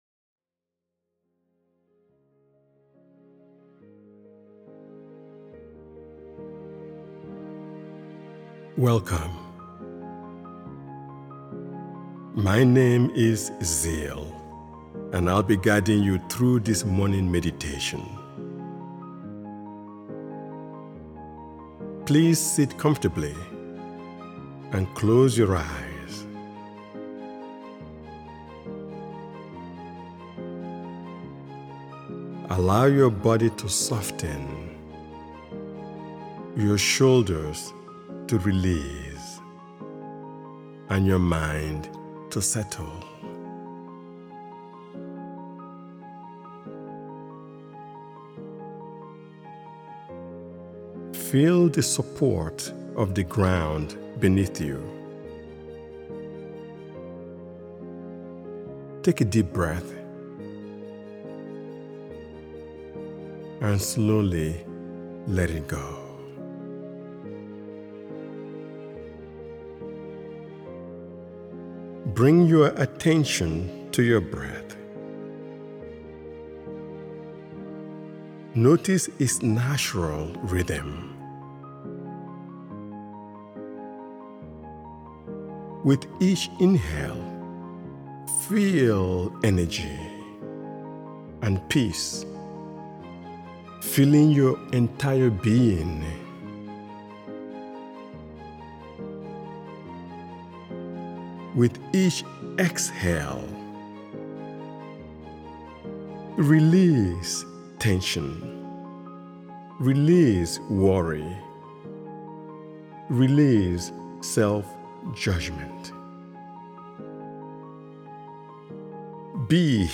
Morning Grace: Start Your Day Grounded and Inspired is a gentle guided meditation designed to help you begin each morning with calm awareness, gratitude, and quiet confidence.